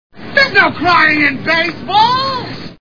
A League of Their Own Movie Sound Bites